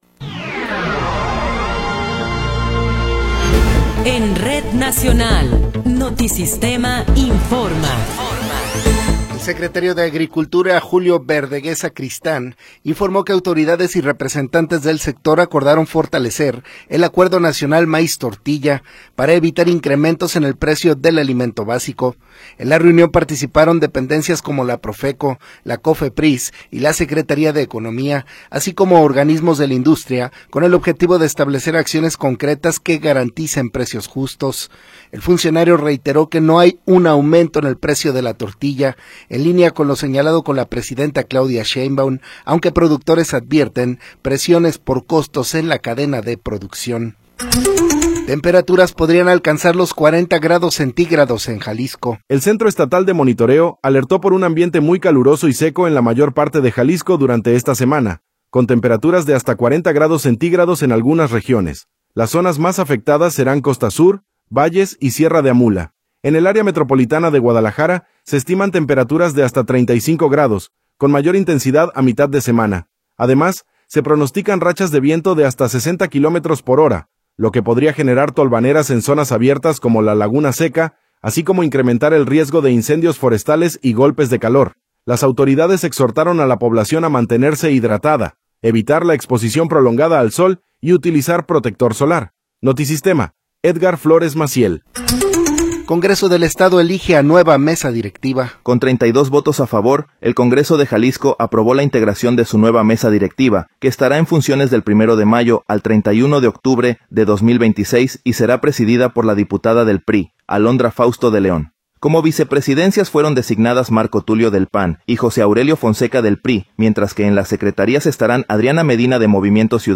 Noticiero 17 hrs. – 15 de Abril de 2026